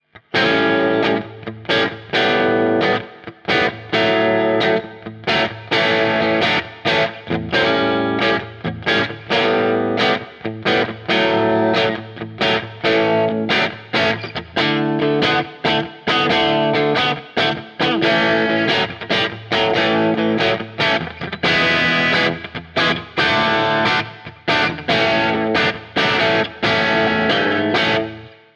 Er liefert einen durchsetzungsfähigen Sound, der sich besonders gut für leicht angezerrte Crunch-Sounds für Rock und Blues eignet. Besonders in den Höhen liefert der P90D Dog Ear eine Menge Biss, ganz wie man es von einer SG Junior erwartet.
Leichter Blues Crunch Sound